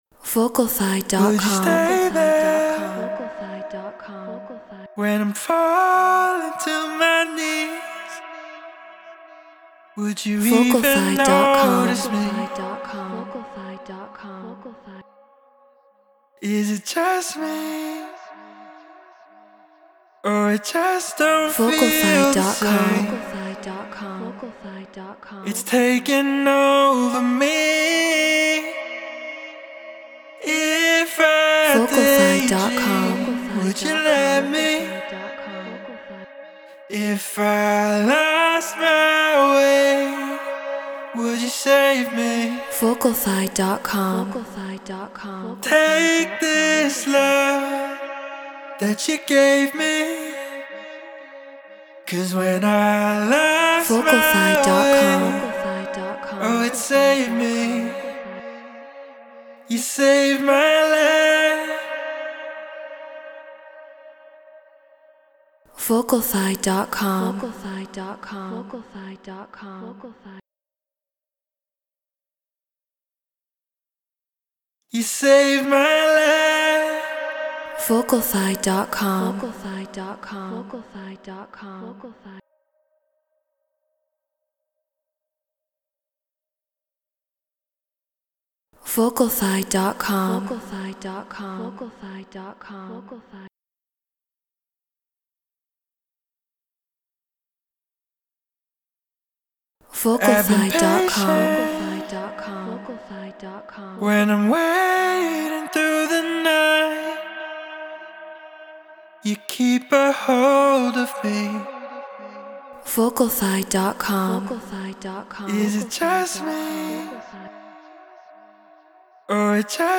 Melodic Techno 126 BPM G#min
RØDE NT1 Focusrite Scarlett Solo FL Studio Treated Room